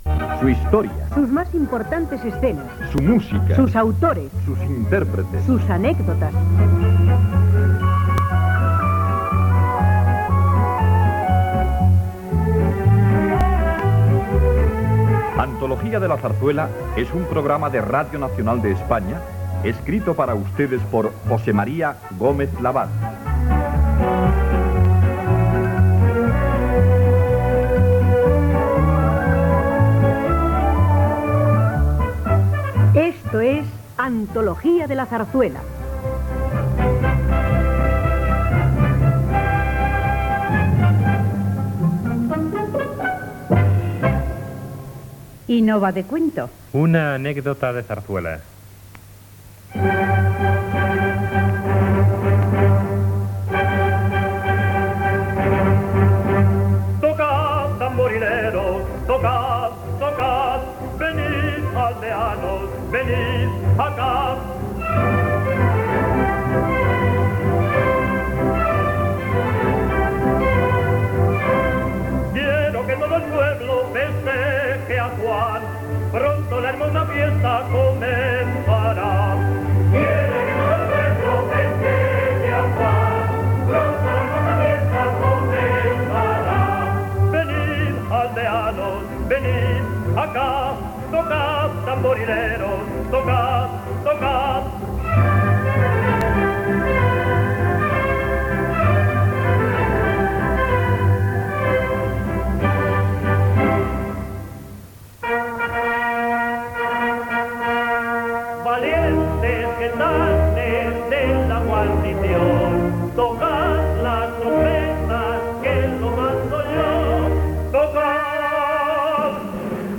Careta del programa, "Una anécdota de Zarzuela" decicada a l'obra "Los gavilanes" i el seu personatge Clariván
Musical